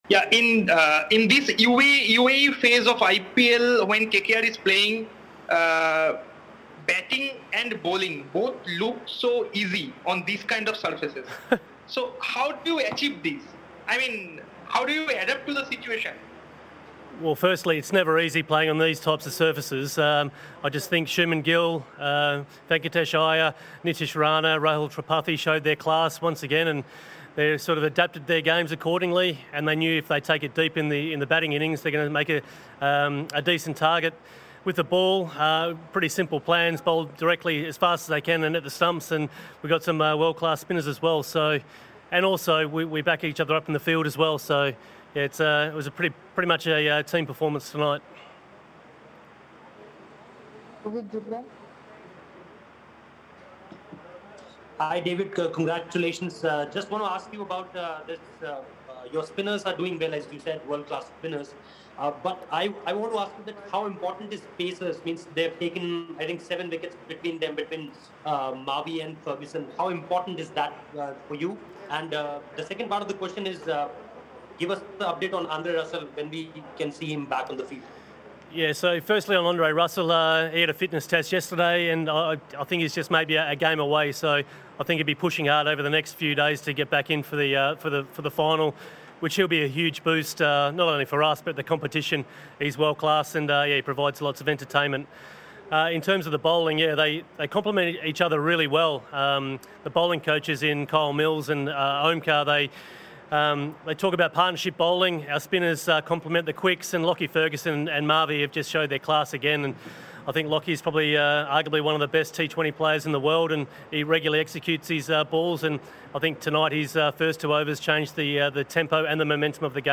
David Hussey, Chief Mentor of Kolkata Knight Riders addressed the media after the game